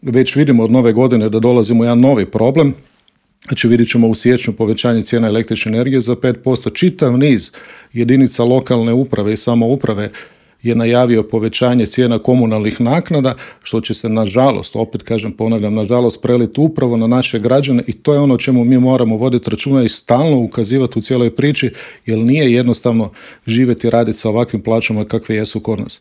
Gostujući u Intervjuu Media servisa